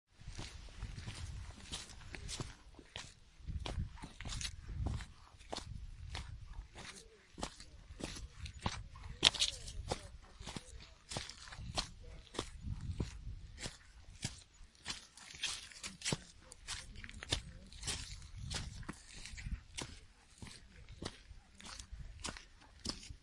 行走的声音 1 " 泥土叶子行走的沉重鞋子
描述：泥叶走重鞋
Tag: 步行 叶子